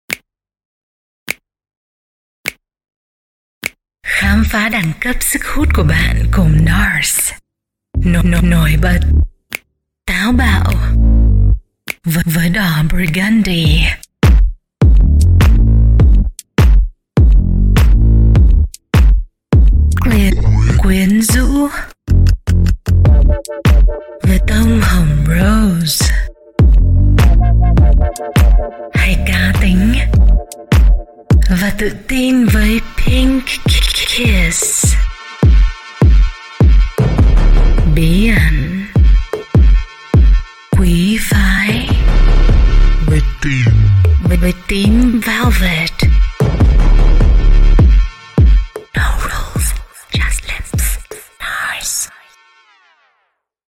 时尚广告-魅力女声